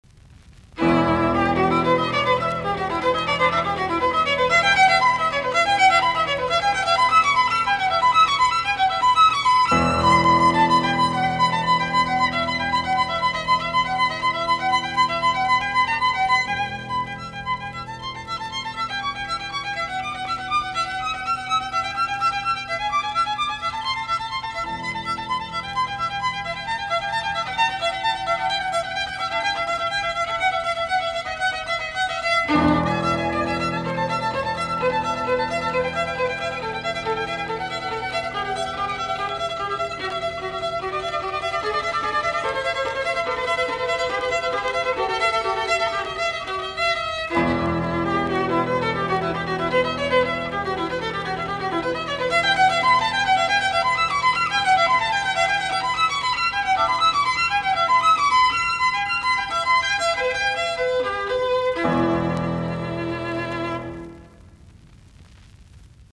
viulu, piano
1. Allegro